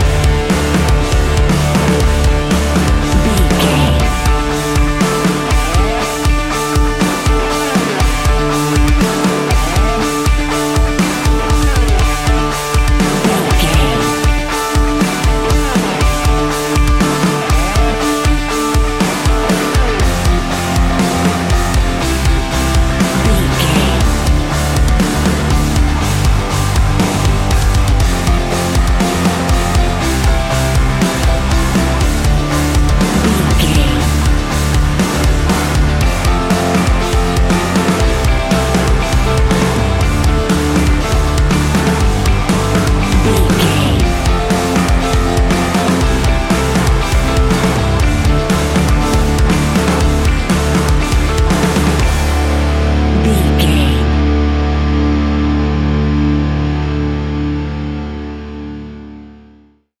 Ionian/Major
D♭
hard rock
heavy metal
instrumentals